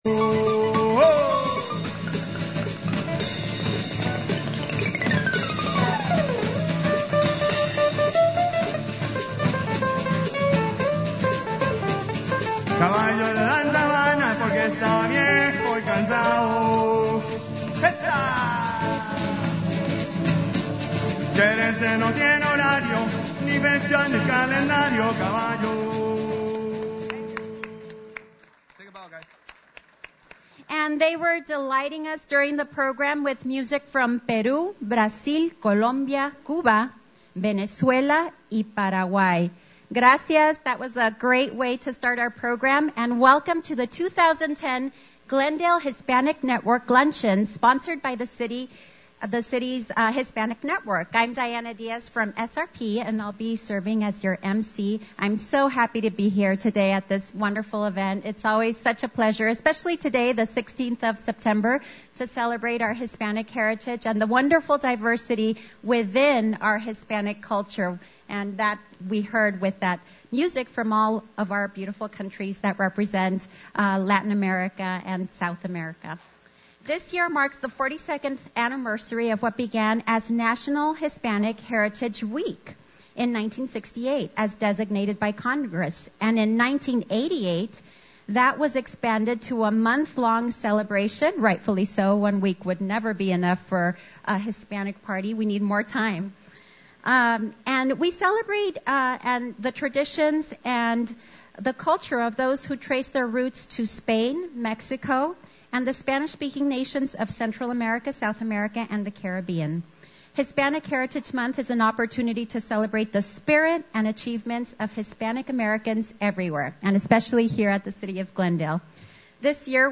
GLENDALE CELEBRATES HISPANIC HERITAGE MONTH Glendale Hispanic Network Luncheon Sept. 16
Both groups featured music and dance from several Latin countries, including Columbia, Cuba, and African influenced-styles.